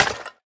Minecraft Version Minecraft Version snapshot Latest Release | Latest Snapshot snapshot / assets / minecraft / sounds / block / decorated_pot / shatter3.ogg Compare With Compare With Latest Release | Latest Snapshot
shatter3.ogg